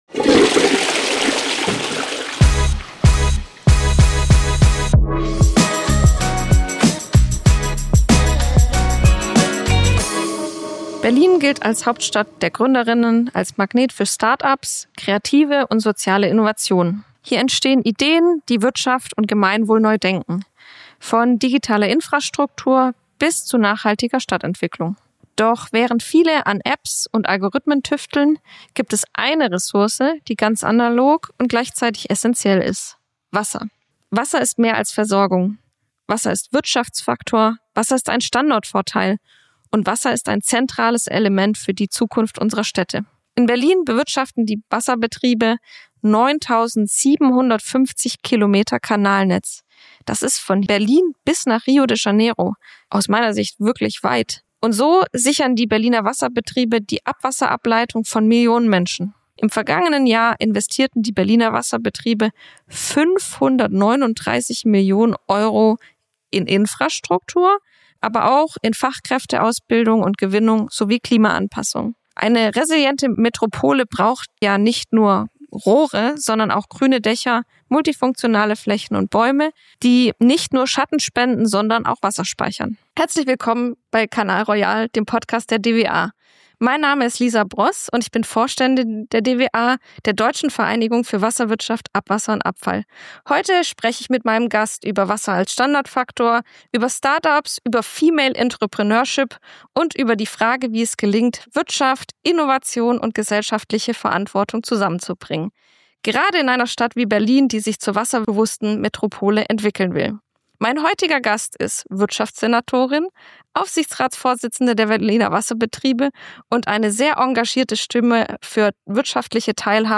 spricht mit Franziska Giffey – Wirtschaftssenatorin in Berlin und Aufsichtsratsvorsitzende der Berliner Wasserbetriebe: Warum wird Wasser ein immer wichtigerer Standortfaktor. Welche Bedeutung haben kommunale Unternehmen für soziale Gerechtigkeit. Wie nutzt Berlin Wasser als zentrales Element für die Klimaanpassung?